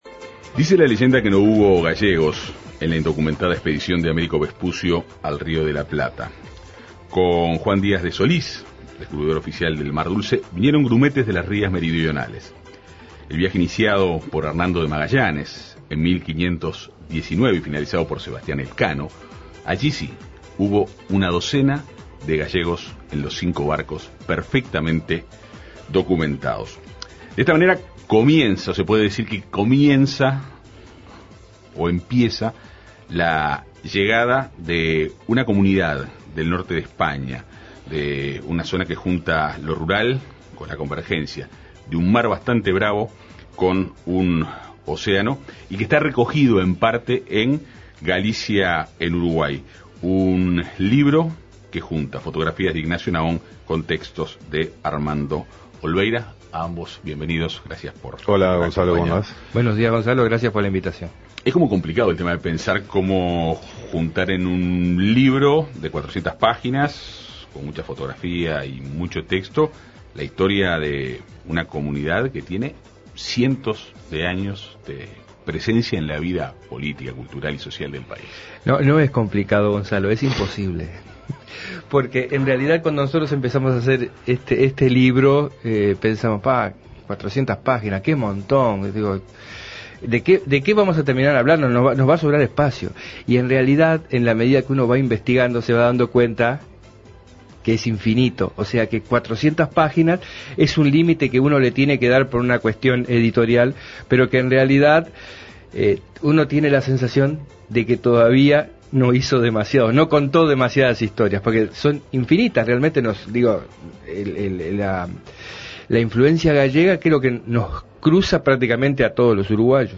dialogaron en la Segunda Mañana de En Perspectiva.